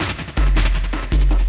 53LOOP01SD-L.wav